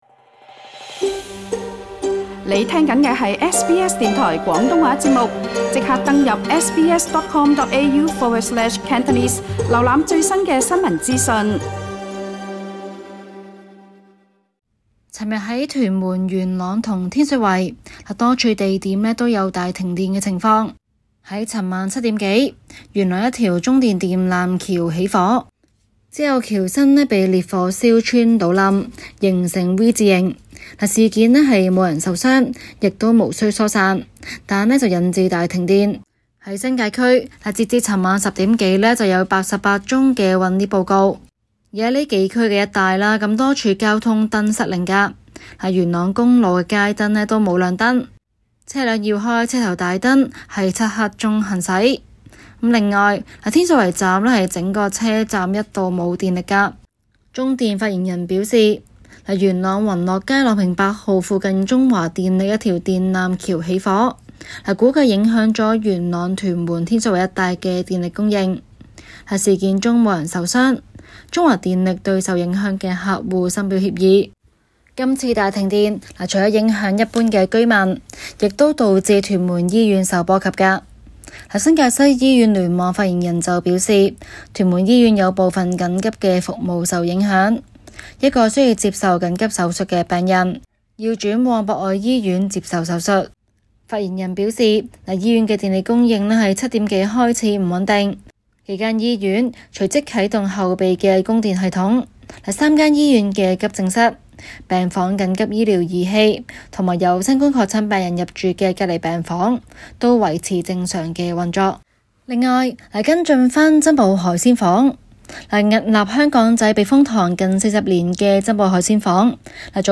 中港快訊